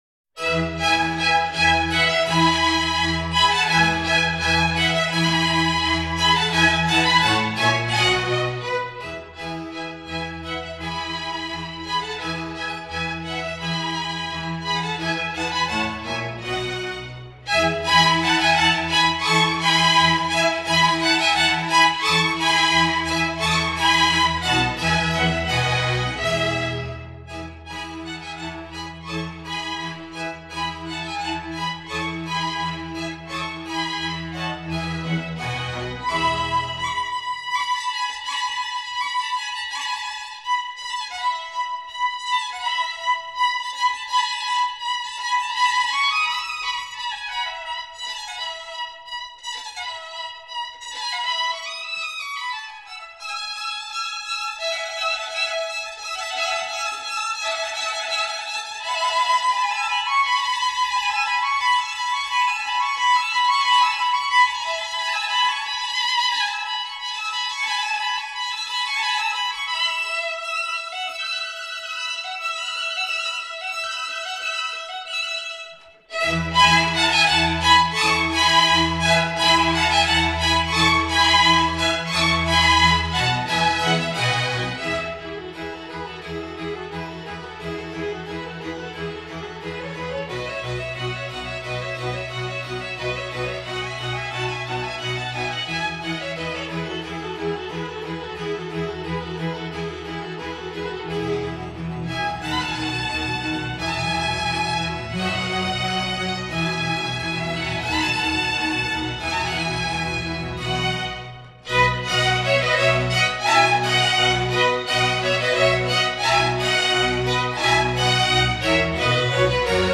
Antonio vivaldi the four seasons spring no 1 op 8 rv269 allegro a vivaldi hamburg chamber orchestraAntonio vivaldi  (4.32 Mo)
antonio-vivaldi-the-four-seasons-spring-no.1-op.8-rv269-allegro-a.-vivaldi-hamburg-chamber-orchestra.mp3